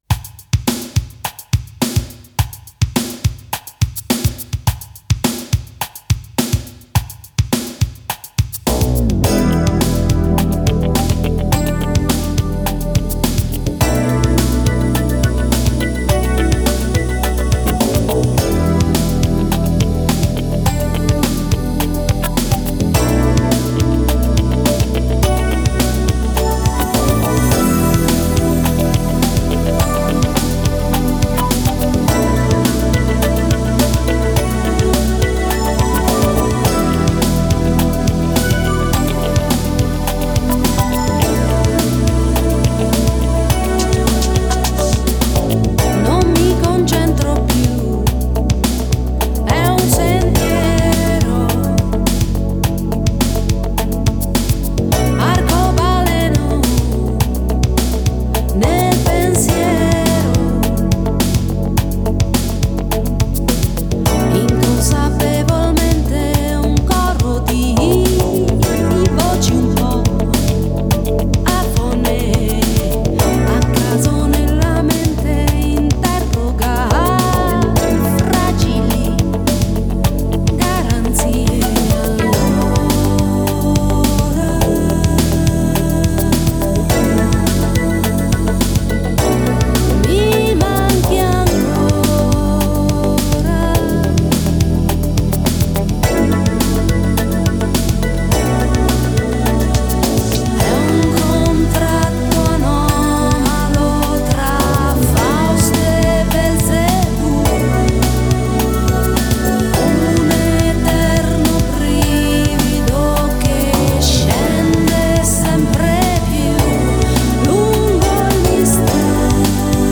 Жанр: Pop, Europop,Vocal,Shlager